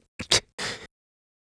Riheet-Vox_Sad_kr-02.wav